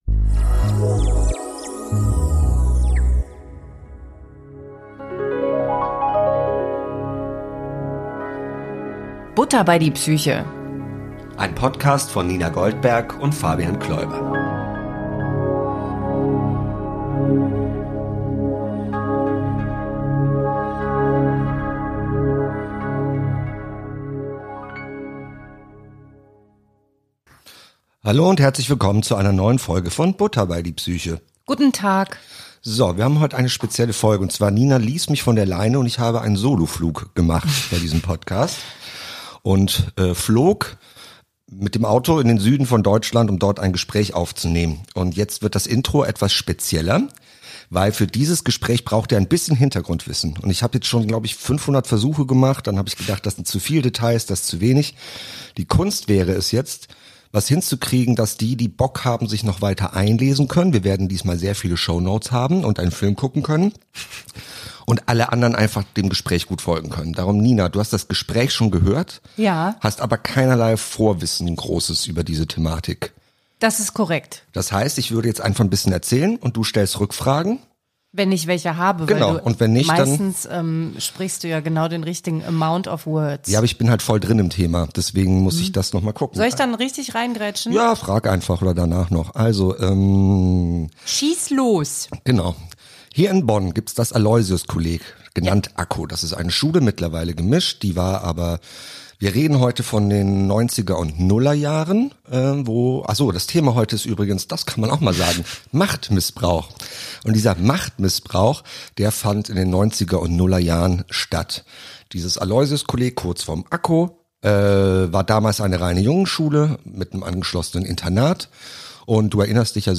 (inkl. thematisch passendem Feuerknacken :) )